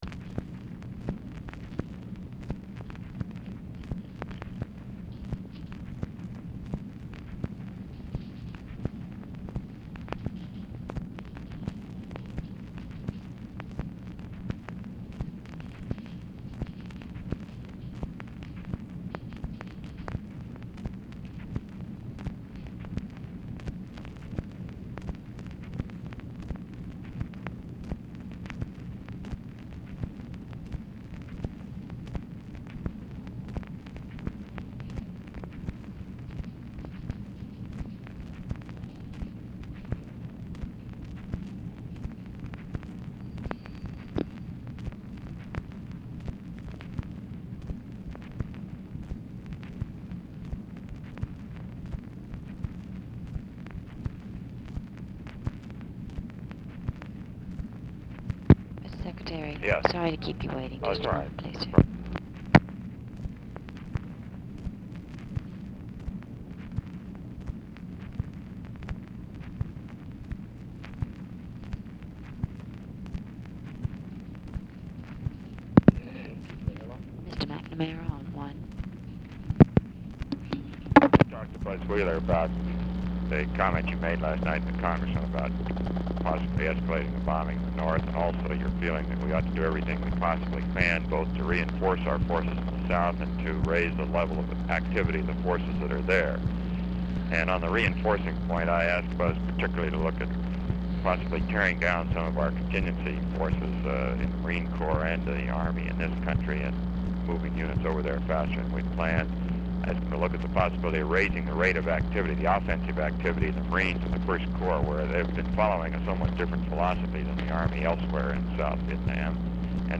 Conversation with ROBERT MCNAMARA, February 16, 1967
Secret White House Tapes